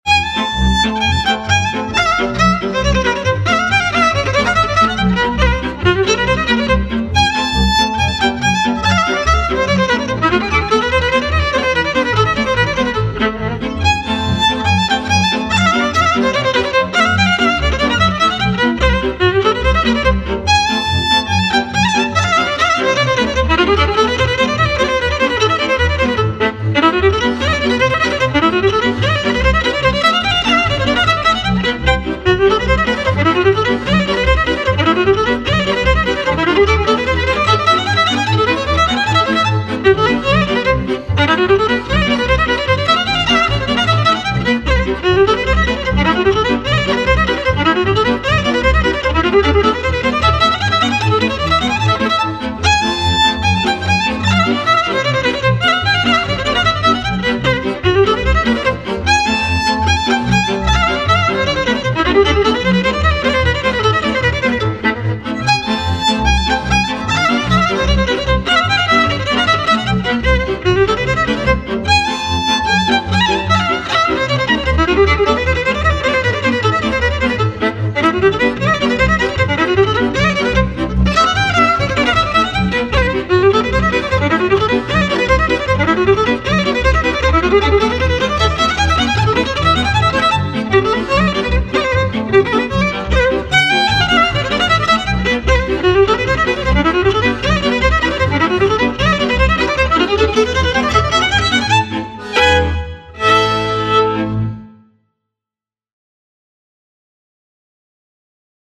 - realizează o bună omogenitate timbrală datorată celor trei instrumente din categoria “cu  coarde şi arcuş”
contrabasul  – frecvenţele grave
braciul cu trei coarde – frecvenţele medii
vioara – frecvenţele înalte